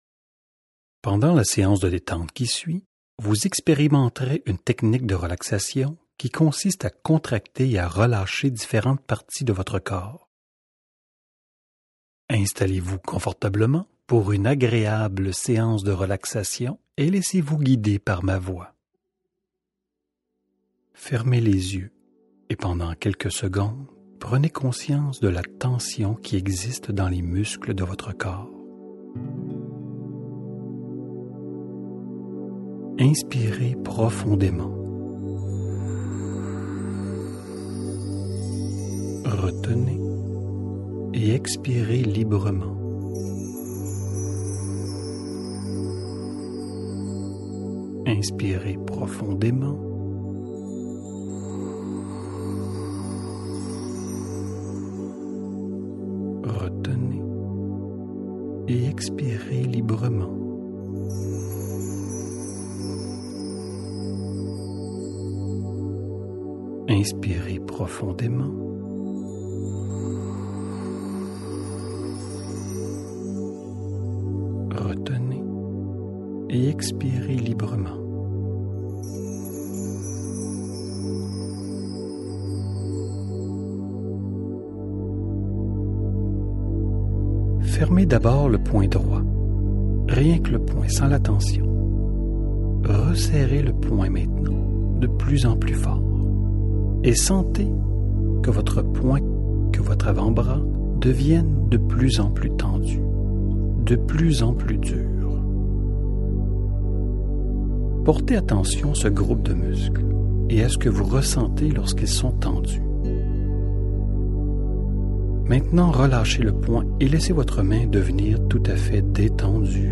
relaxation-jacobson.mp3